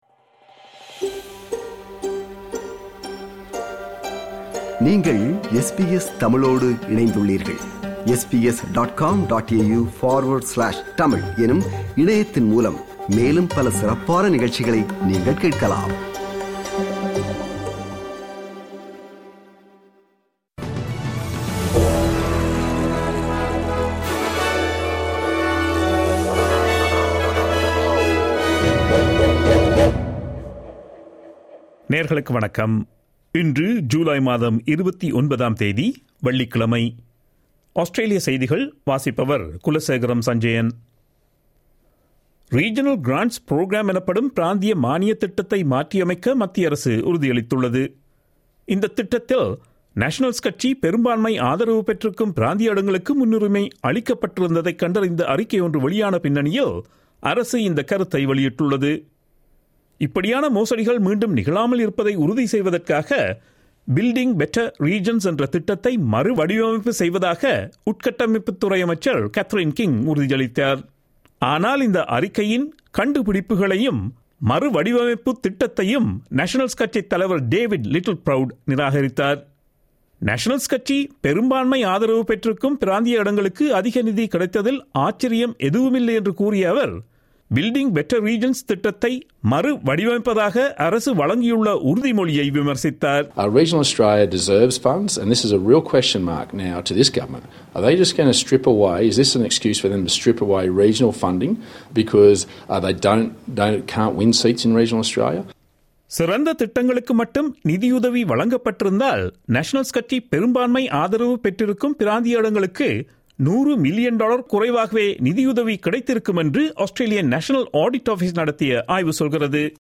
Australian news bulletin for Friday 29 July 2022.